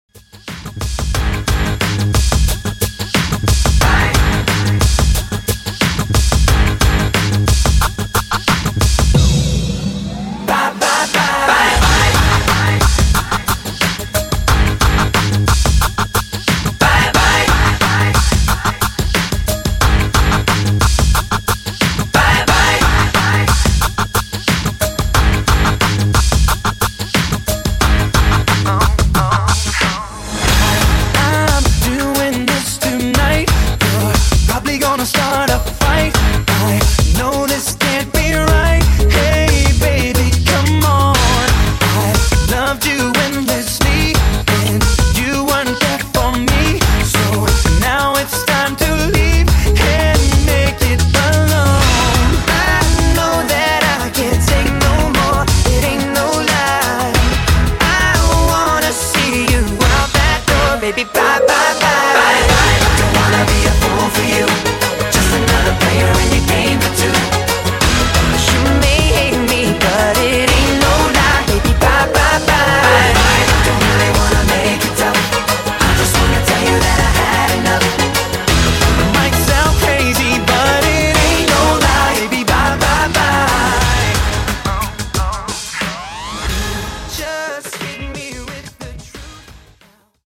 Dj Intro Edit V3)Date Added